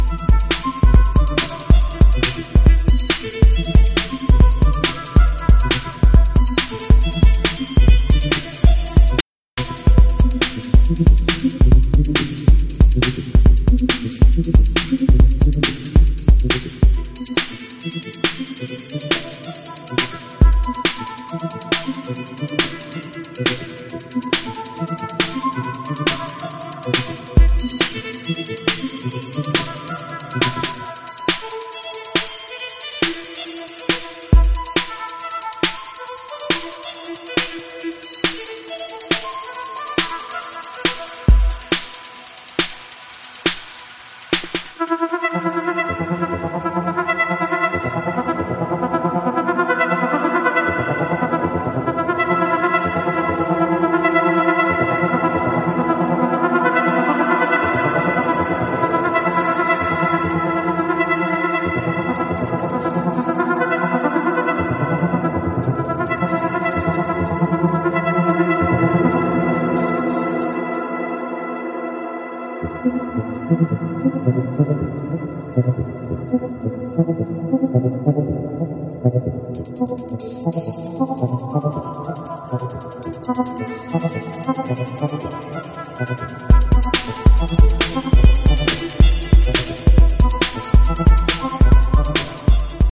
Highly diversified techno deep space explorations.